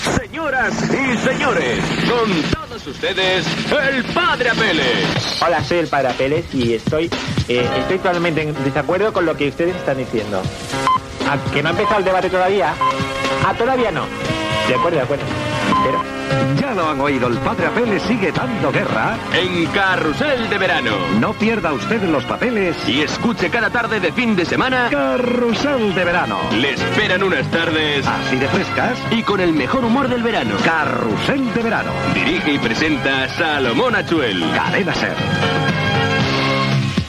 Gravació realitzada a València.